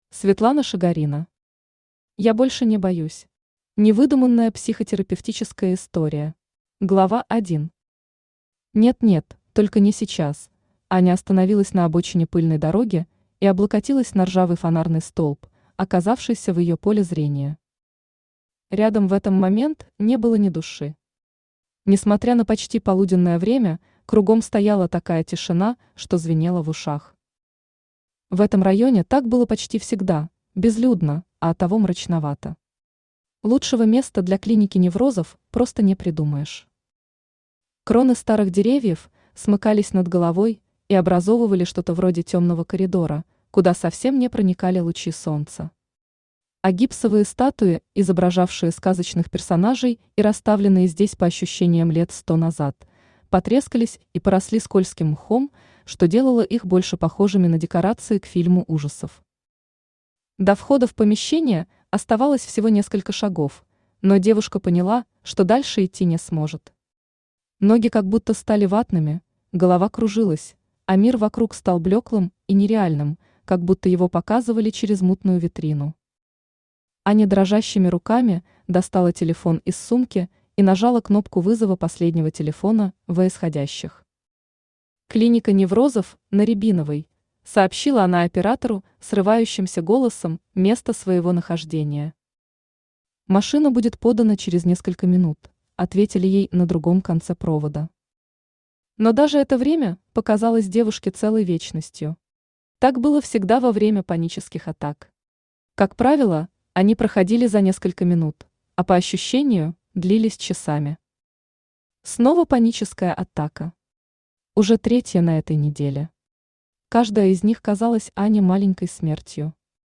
Невыдуманная психотерапевтическая история Автор Светлана Шигорина Читает аудиокнигу Авточтец ЛитРес.